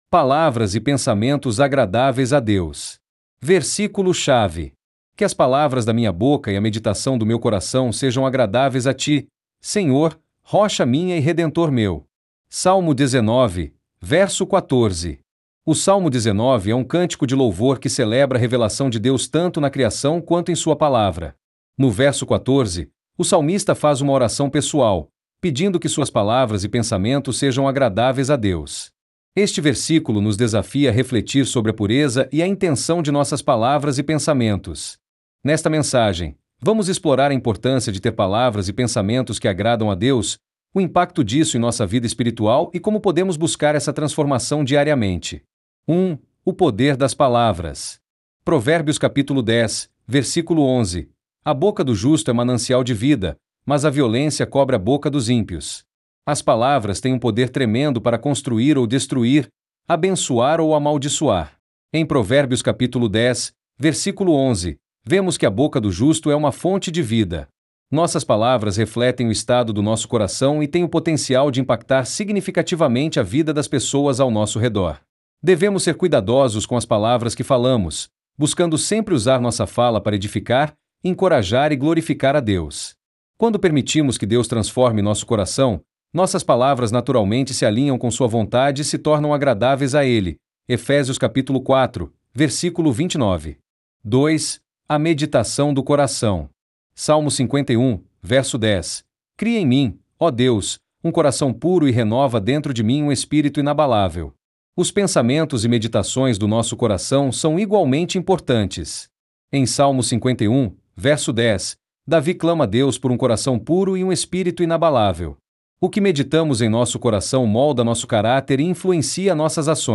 DEVOCIONAL